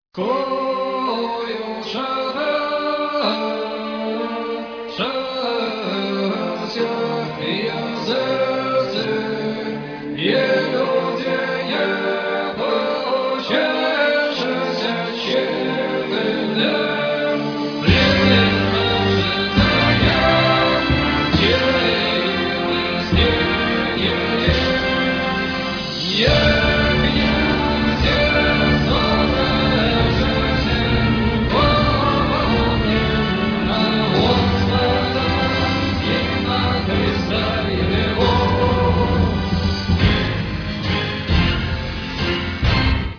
фрагмент выступления ( 45 сек.)